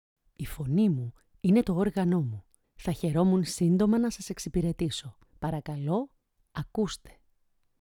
Female
Adult (30-50)
I have a unique voice, soft, friendly, warm, nice and unforgettable. As an actress I can change my voice easily to many variations and characters.
All our voice actors have professional broadcast quality recording studios.